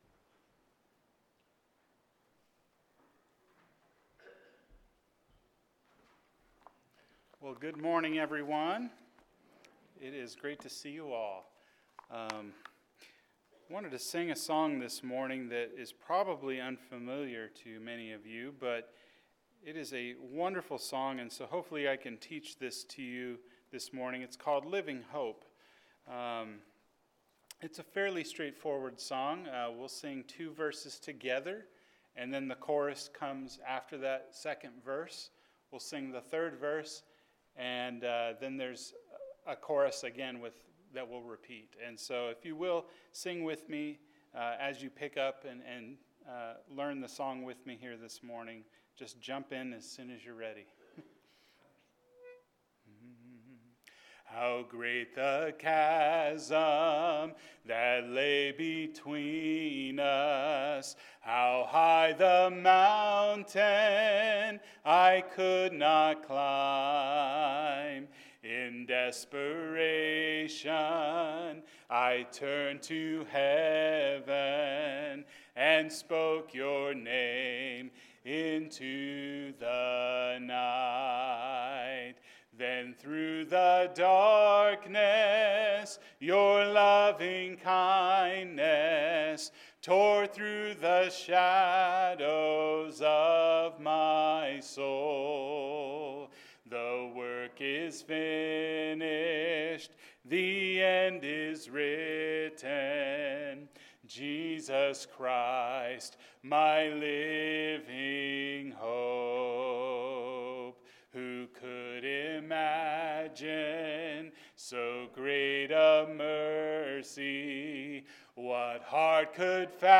Seven — Salvation – Sermon